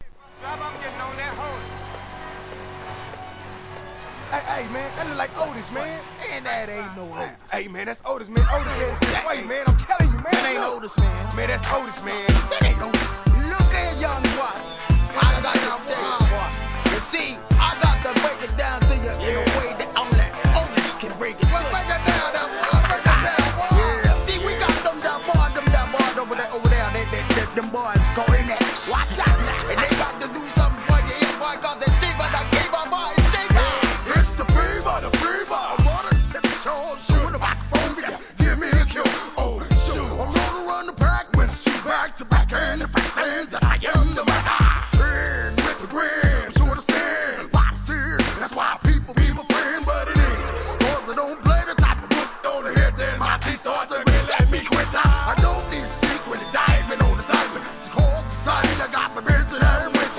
G-RAP/WEST COAST/SOUTH
1997年、CA.産良質ミドルG-FUNK!!